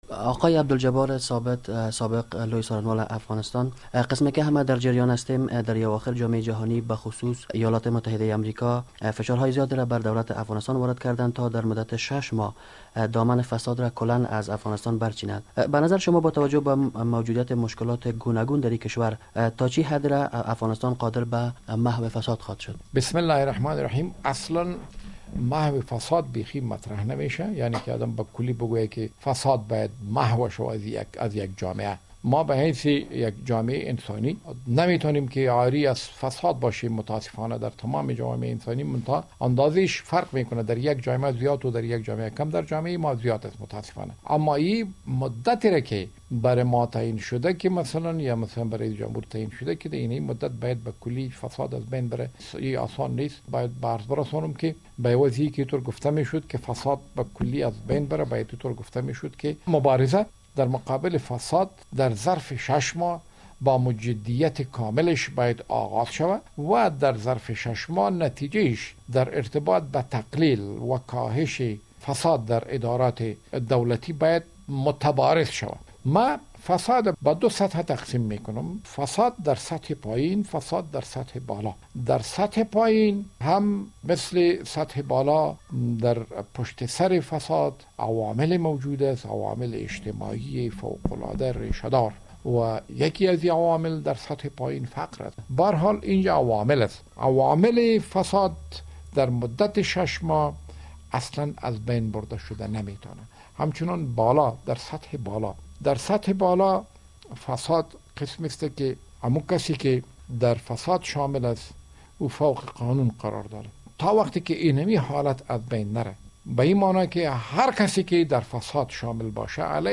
مصاحبه با عبدالجبار ثابت لوی څارنوال سابق افغانستان